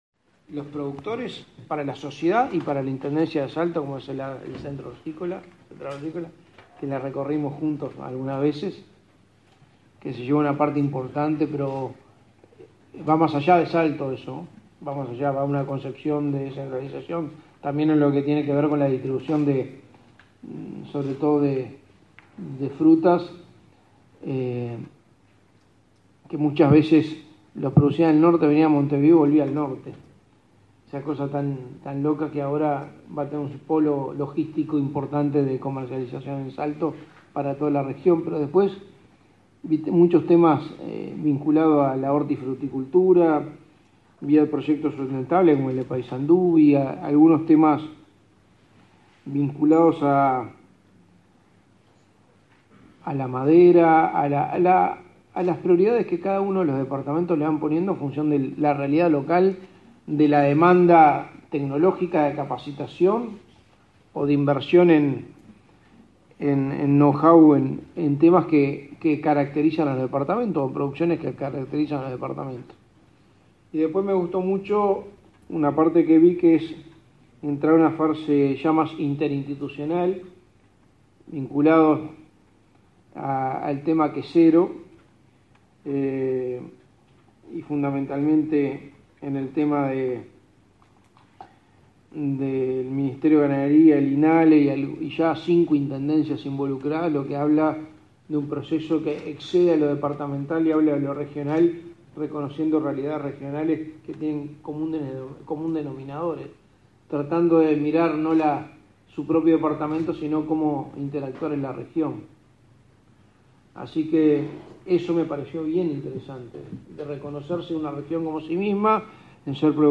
Palabras del secretario de Presidencia, Álvaro Delgado
Este 23 de noviembre, el secretario Álvaro Delgado participó en el encuentro con los 19 directores de Desarrollo Económico de las intendencias